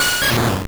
Cri de Kapoera dans Pokémon Or et Argent.